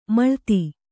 ♪ maḷti